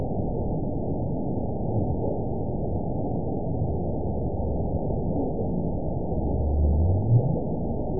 event 917902 date 04/20/23 time 20:58:00 GMT (2 years, 7 months ago) score 9.22 location TSS-AB04 detected by nrw target species NRW annotations +NRW Spectrogram: Frequency (kHz) vs. Time (s) audio not available .wav